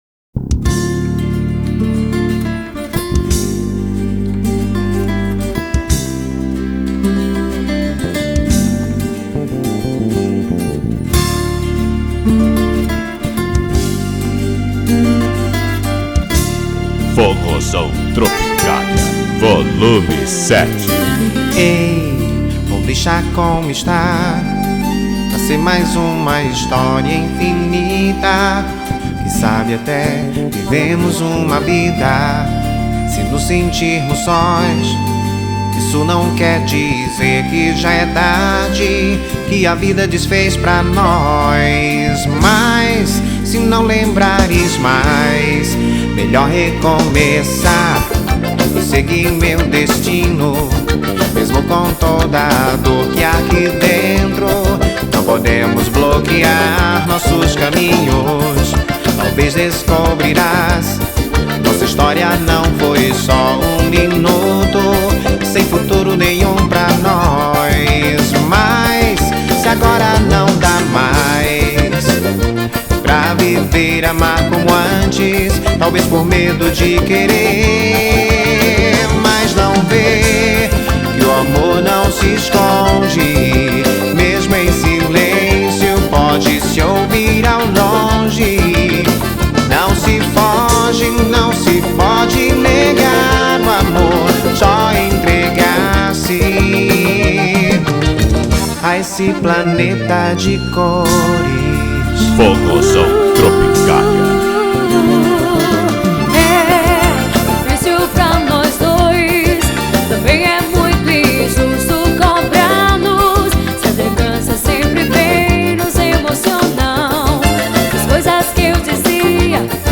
2024-12-30 10:49:58 Gênero: Forró Views